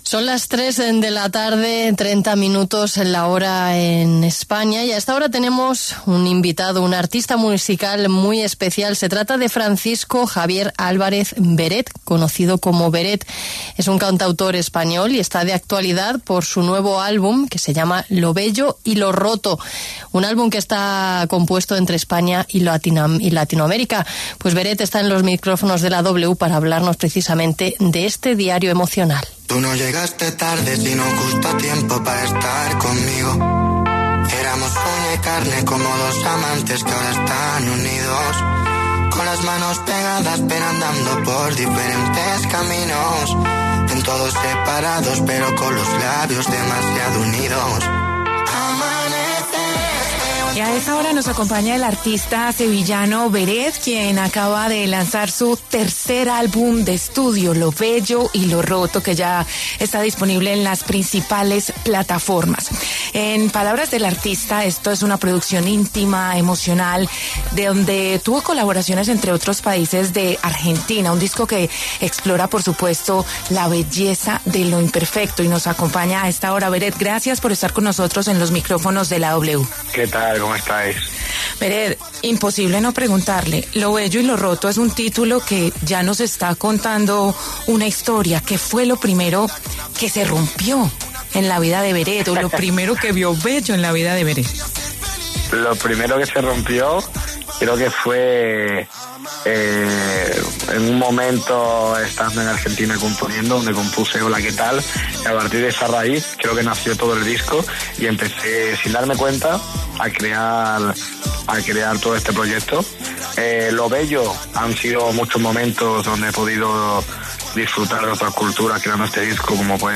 El cantante sevillano Beret pasó por los micrófonos de la W para hablar de su nuevo álbum que estuvo componiendo entre España, México, Colombia y Argentina.